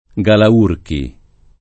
[ g ala 2 rki ]